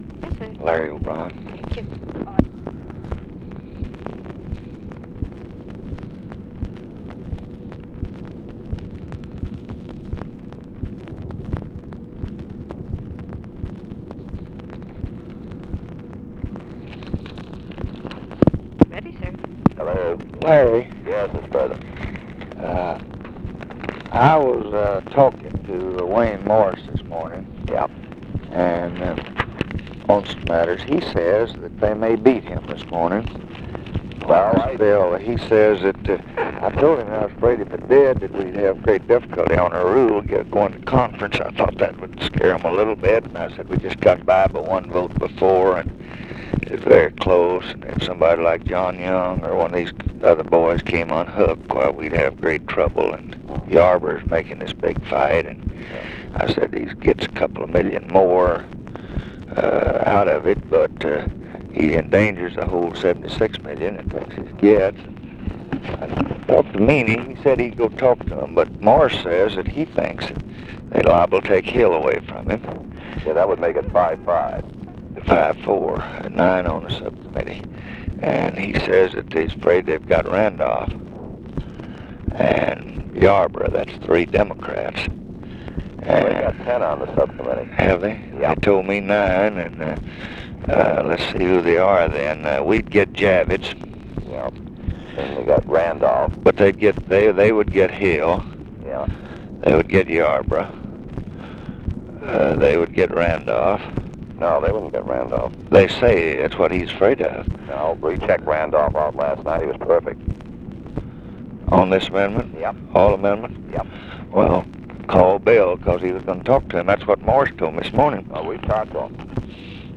Conversation with LARRY O'BRIEN, April 1, 1965
Secret White House Tapes